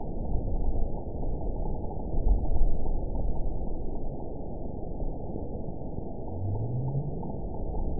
event 920578 date 03/31/24 time 00:48:59 GMT (1 year, 1 month ago) score 9.53 location TSS-AB01 detected by nrw target species NRW annotations +NRW Spectrogram: Frequency (kHz) vs. Time (s) audio not available .wav